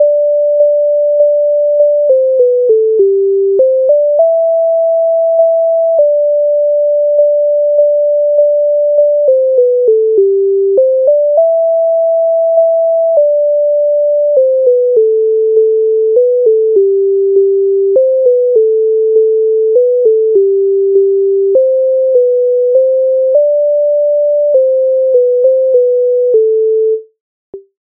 MIDI файл завантажено в тональності G-dur
Женчичок бренчичок Українська народна пісня зі збірки Михайловської
Ukrainska_narodna_pisnia_Zhenchychok_brenchychok.mp3